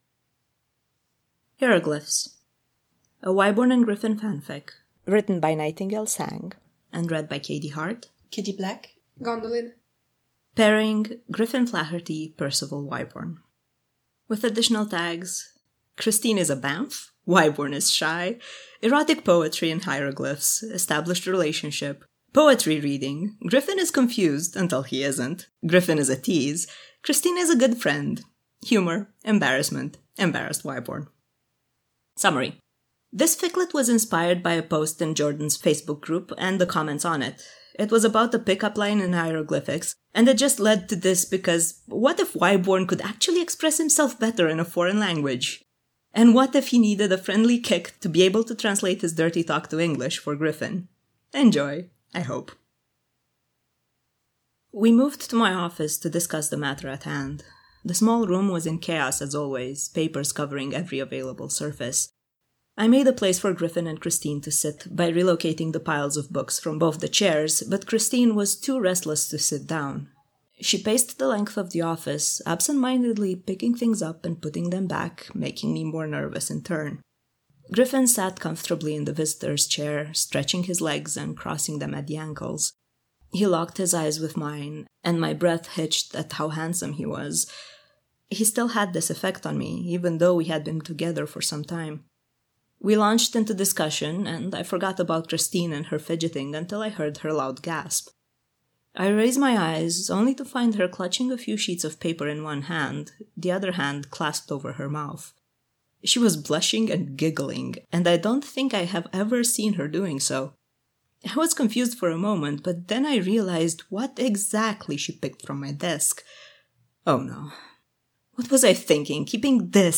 Recorded live at EPF2019